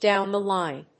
アクセントdówn the líne 《米口語》